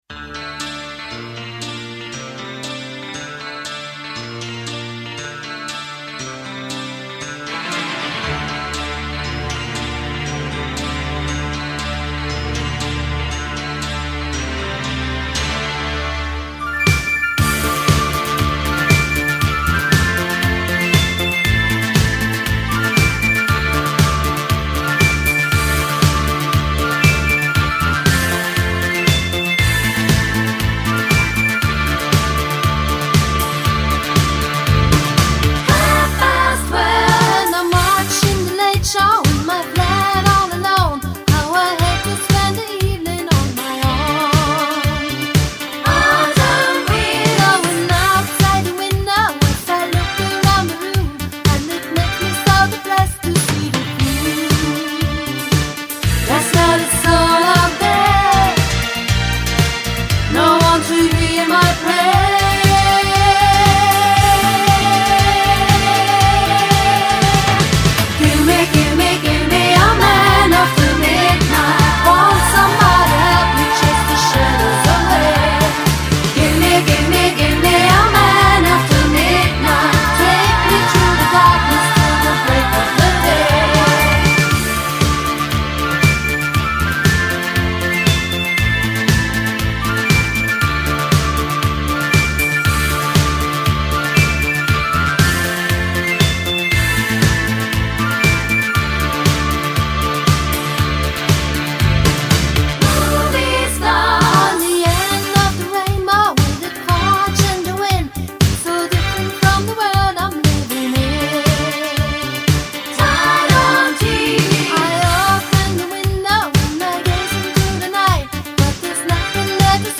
• Sänger/in